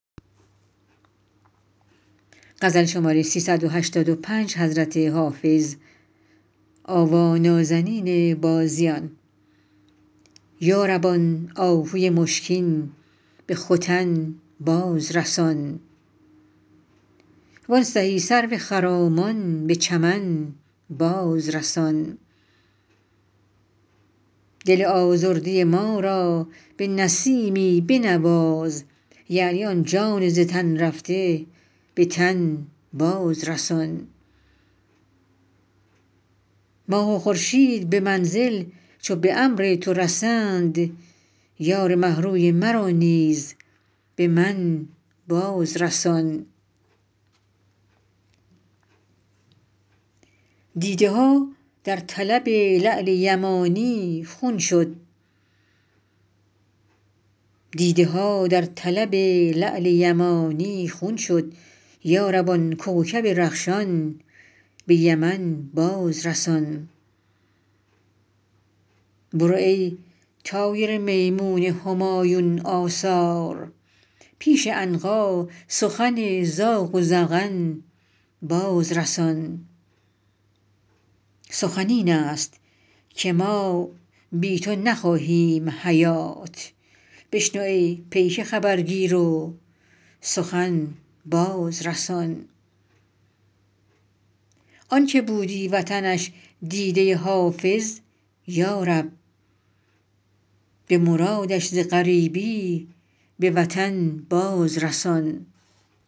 حافظ غزلیات غزل شمارهٔ ۳۸۵ به خوانش